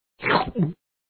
eat.wav